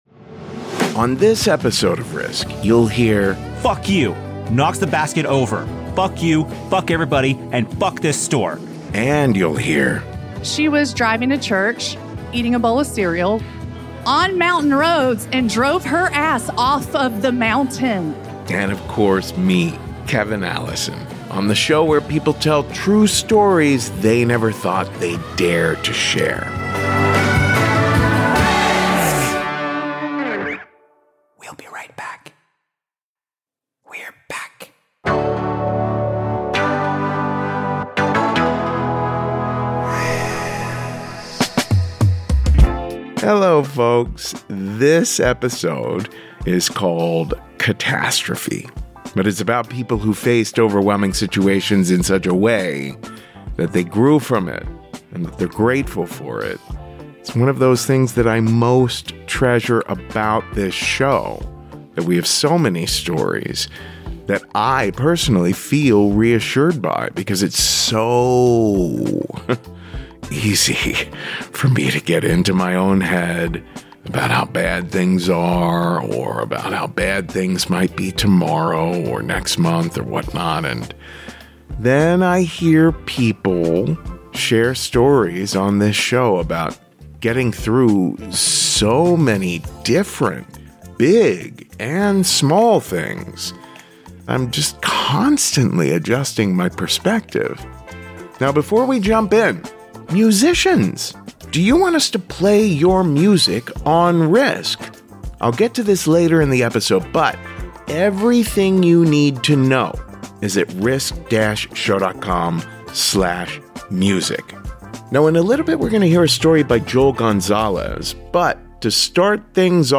🎤Live Story: Searching for Blue Sky
🎙Radio Story: Keeping Us Safe